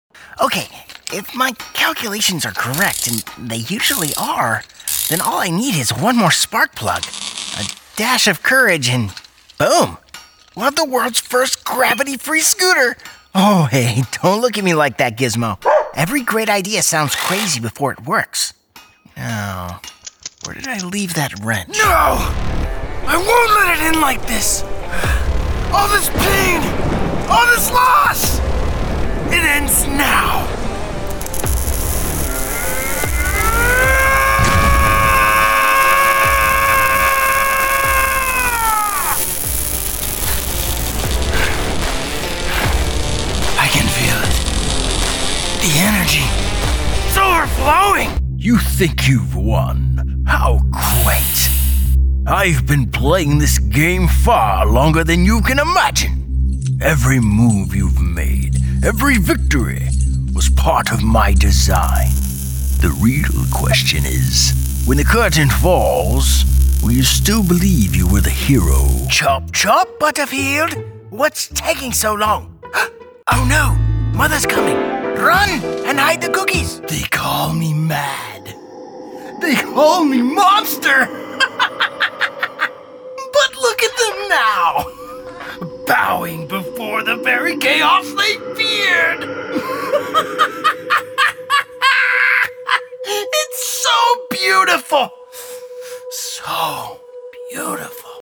Male
My voice is warm, grounded, and naturally reassuring, with a smooth American tone that feels trustworthy the moment it hits the ear.
Character / Cartoon
Dynamic Character Acting
1201Animation_Demo_Compilation_1.mp3